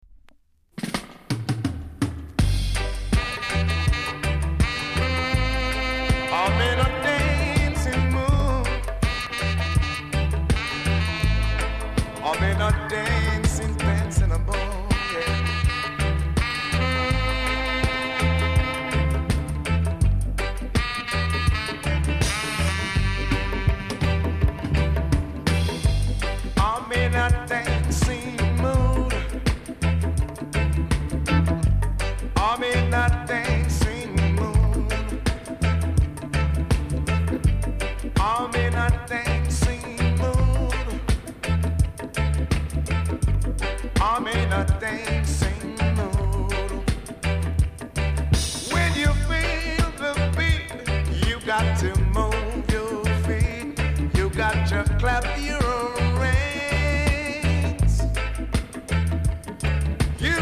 ※小さなチリノイズが少しあります。
コメント GOODセルフリメイク!!